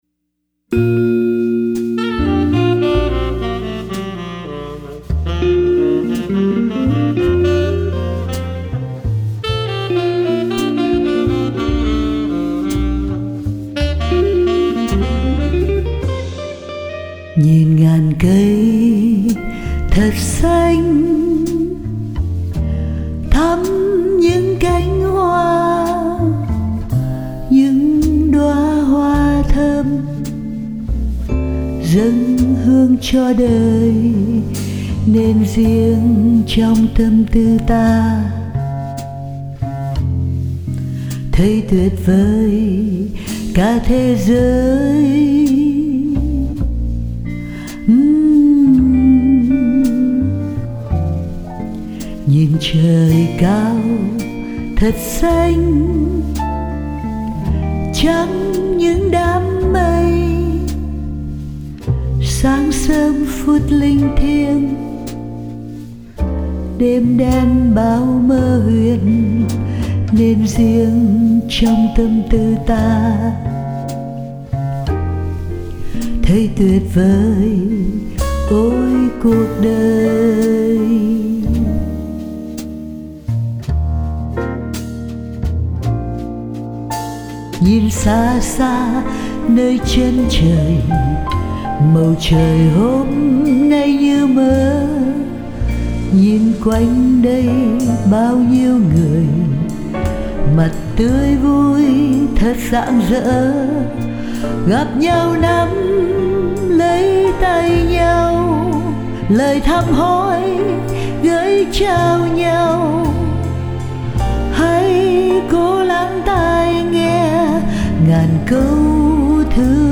già dặn, trầm khàn và vang